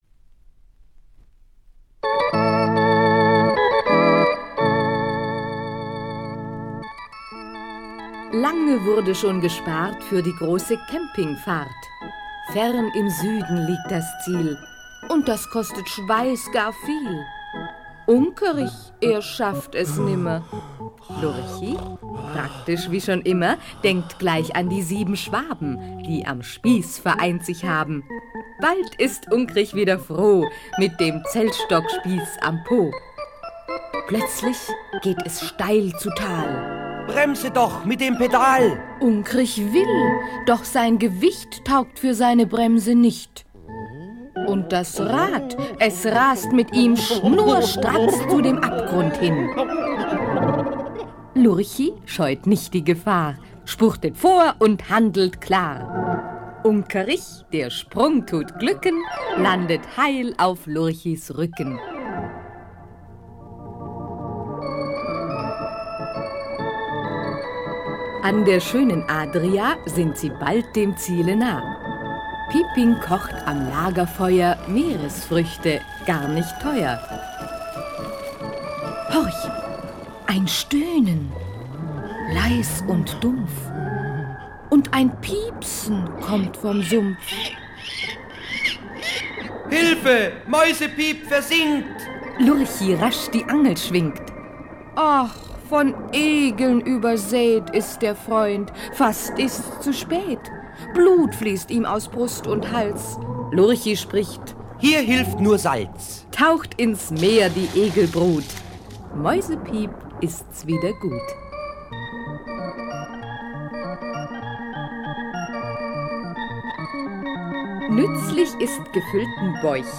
Lurchis Hörspiele
Lurchis Schallplatten